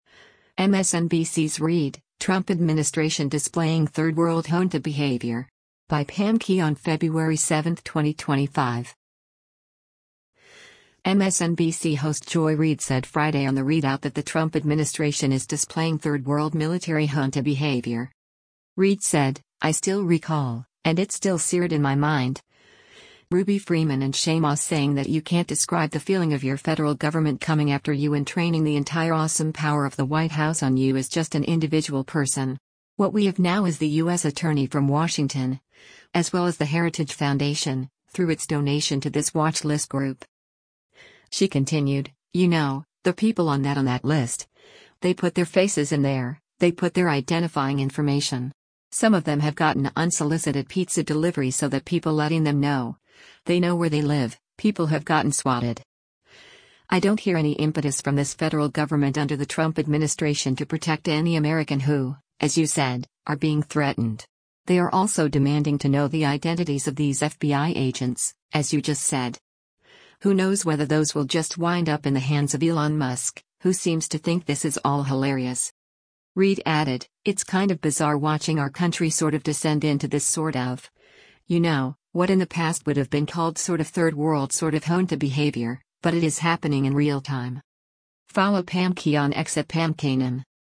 MSNBC host Joy Reid said Friday on “The ReidOut” that the Trump administration is displaying “third world” military “junta behavior.”